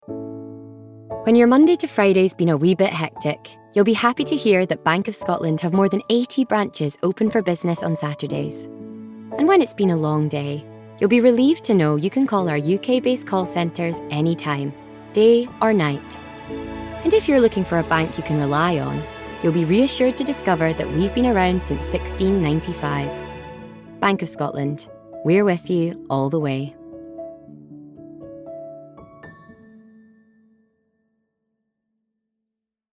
Voice Reel
Bank Of Scotland - Reassuring, Warm, Bright